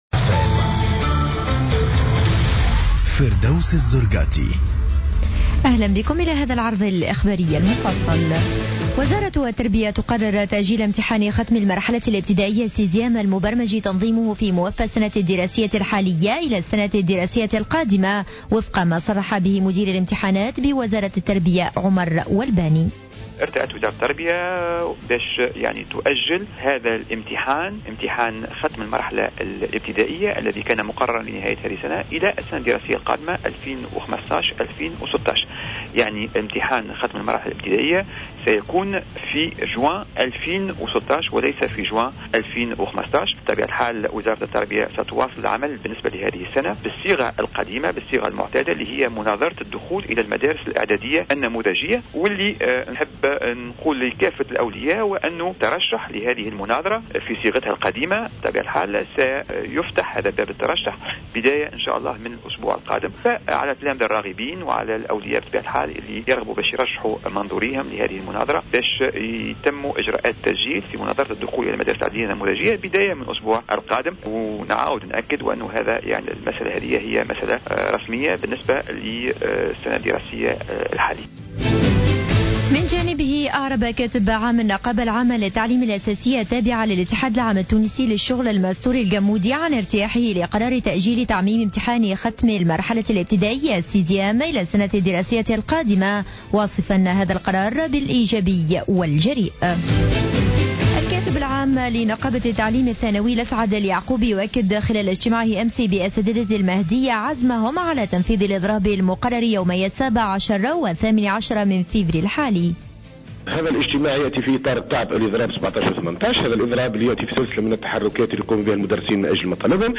نشرة أخبار منتصف الليل ليوم الجمعة 13 فيفري 2015